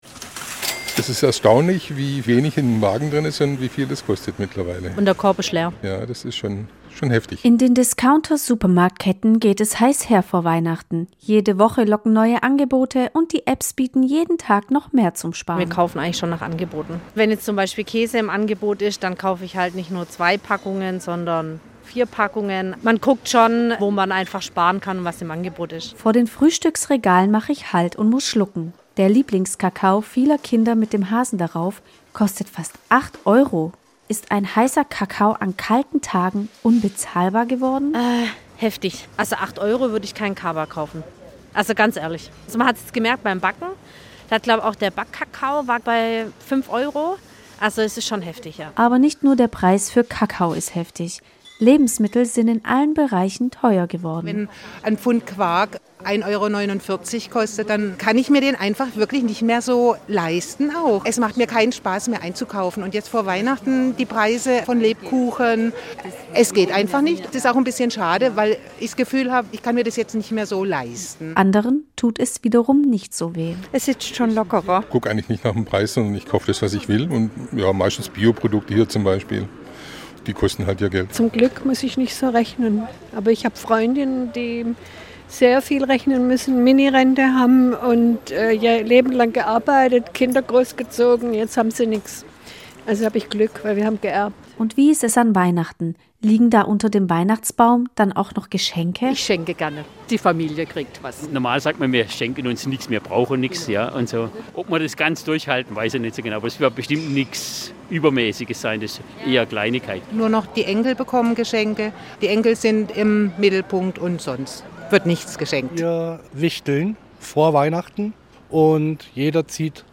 Das nutzen einige Kundinnen und Kunden gezielt, kaufen nach Angeboten und achten darauf, wo sie sparen können, erzählen sie SWR1 in einem Stuttgarter Discounter.